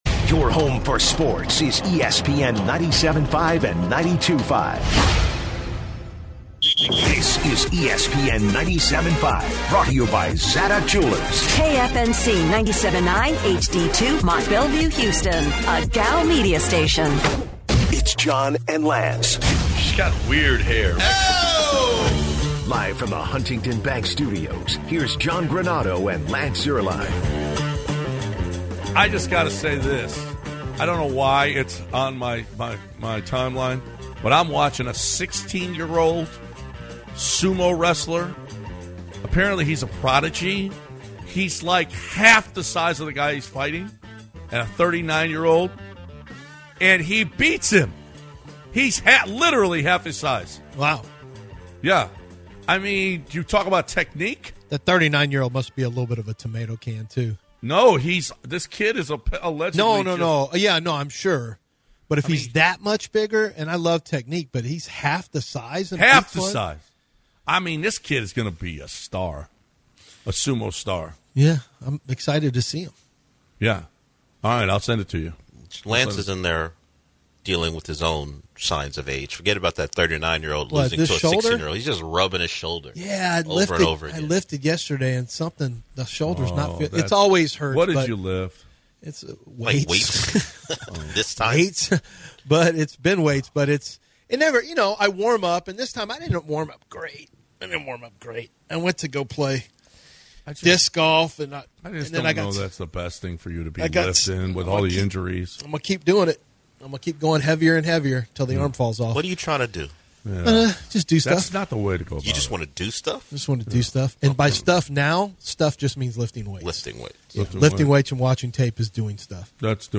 Rockets Theory from Caller